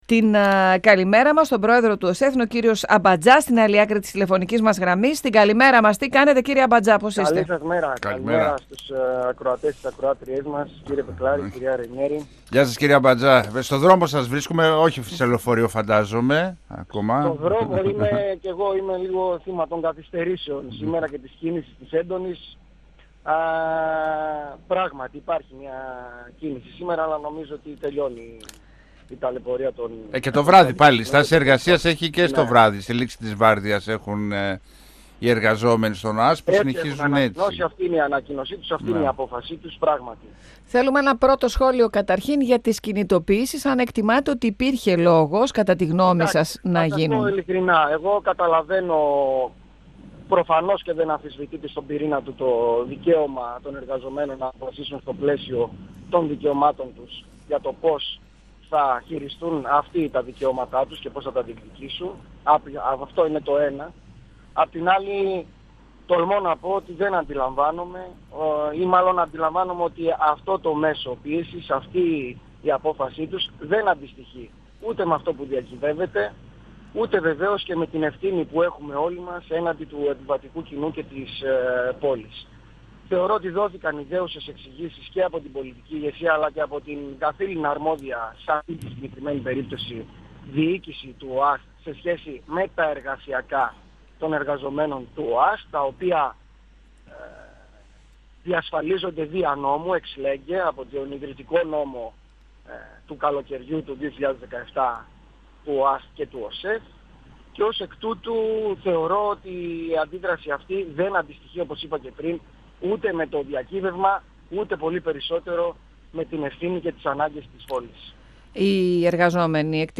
Για μια τροπολογία που ανοίγει το δρόμο ώστε να υπάρξει ένας συνολικός σχεδιασμός για τις συγκοινωνίες στη Θεσσαλονίκη έκανε λόγο μιλώντας στον 102FM του Ραδιοφωνικού Σταθμού Μακεδονίας της ΕΡΤ3 ο πρόεδρος του ΟΣΕΘ, Κώστας Αμπατζάς. Αναφερόμενος στη θαλάσσια συγκοινωνία είπε ότι όταν γίνει συγκοινωνιακό έργο τότε η αρμοδιότητα ανήκει στον ΟΣΕΘ.
Αναφερόμενος στη θαλάσσια συγκοινωνία είπε ότι όταν γίνει συγκοινωνιακό έργο τότε η αρμοδιότητα ανήκει στον ΟΣΕΘ. 102FM Συνεντεύξεις ΕΡΤ3